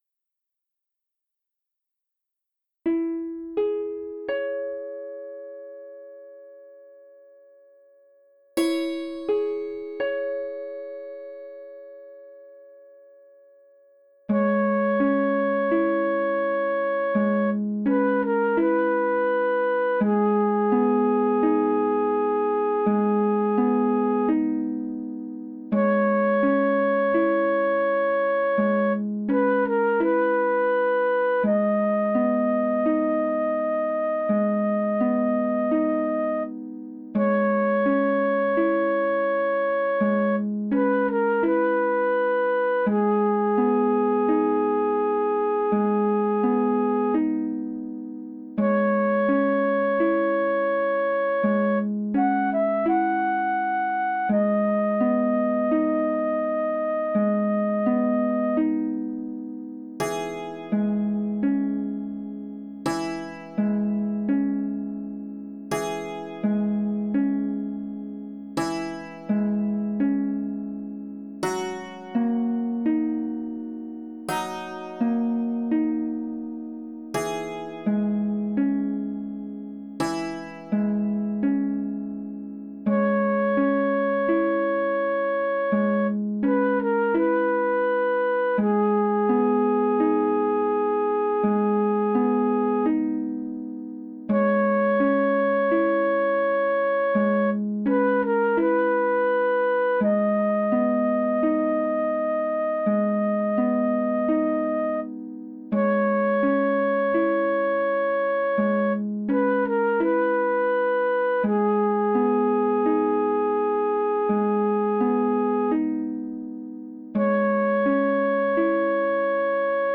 精霊さんが出てきそうなBGMに仕上げました！
ループ：◎
BPM：84 キー：C#ドリアン ジャンル：まほう 楽器：ファンタジー